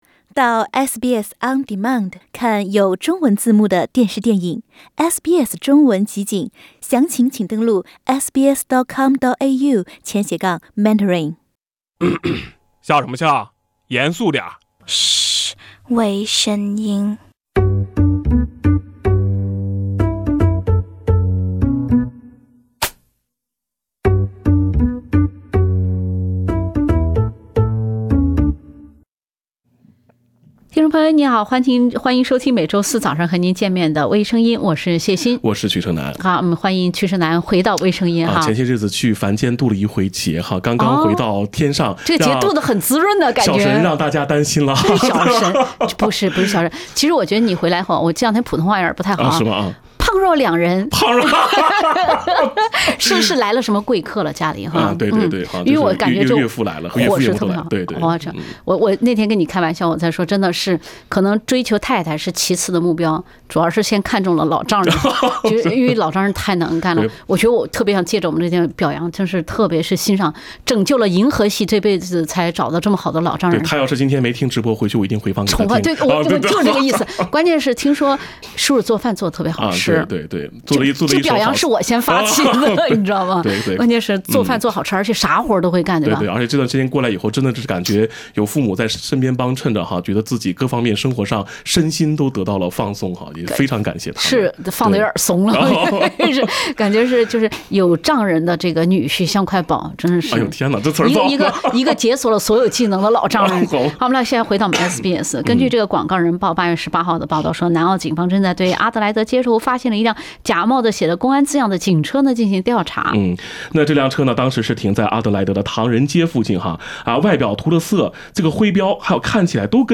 另类轻松的播报方式，深入浅出的辛辣点评，更劲爆的消息，更欢乐的笑点，敬请收听每周四上午8点30分播出的时政娱乐节目《微声音》。